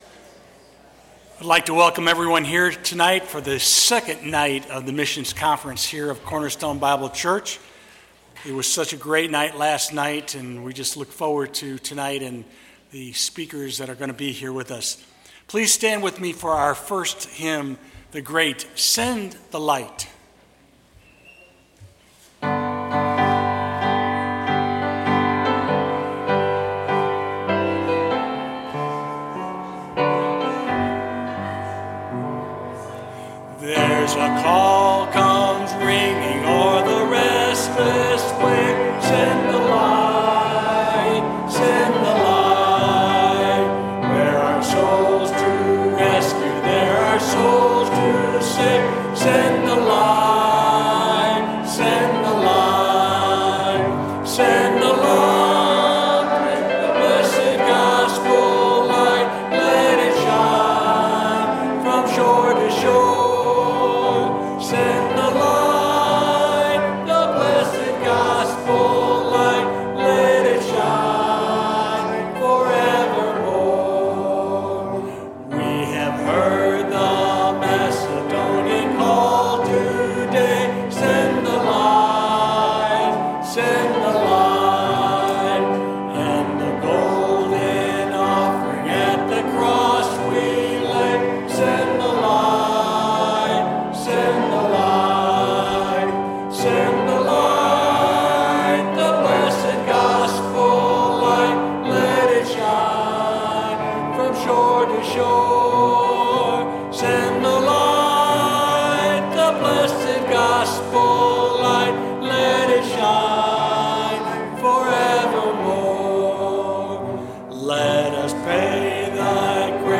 Sermon Detail
2023 Missions Conference - Saturday Evening